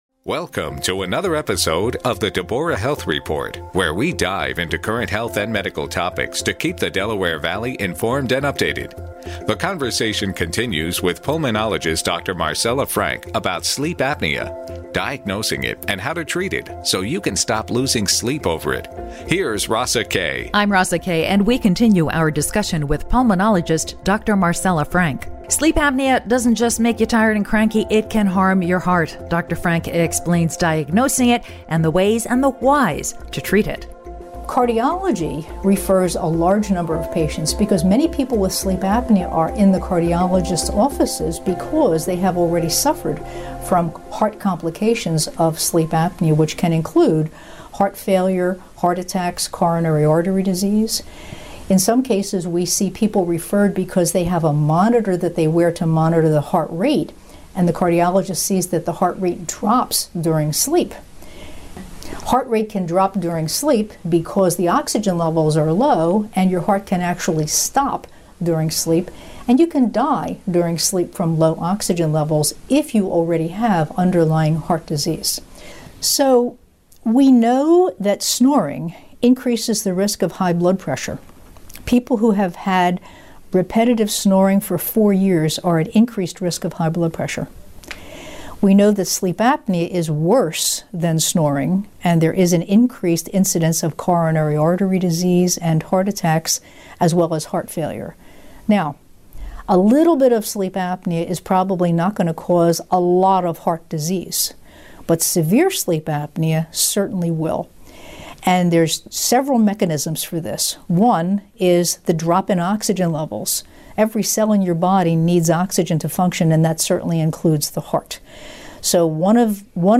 conversation about sleep apnea